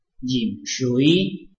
臺灣客語拼音學習網-客語聽讀拼-海陸腔-鼻尾韻
拼音查詢：【海陸腔】rhim ~請點選不同聲調拼音聽聽看!(例字漢字部分屬參考性質)